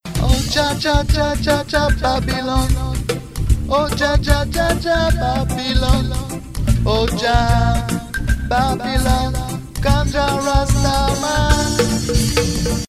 SONNERIES REGGAE